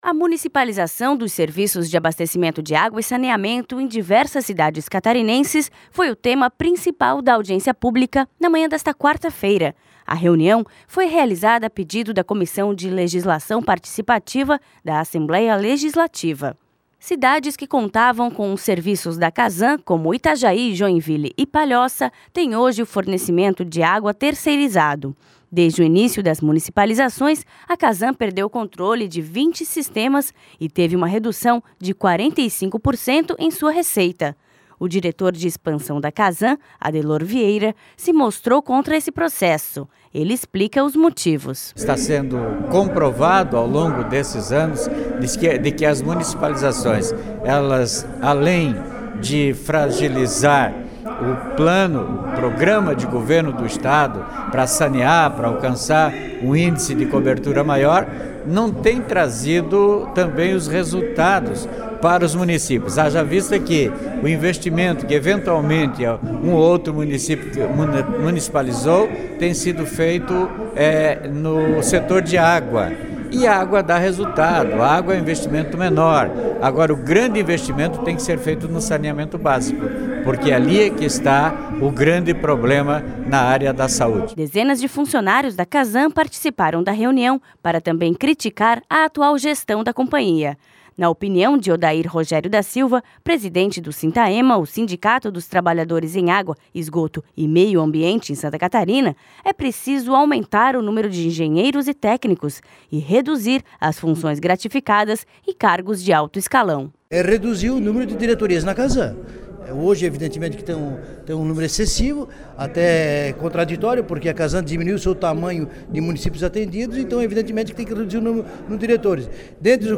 Audiência debate gestão da Casan, terceirização do setor de saneamento e CPI das Águas